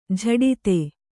♪ jhaḍite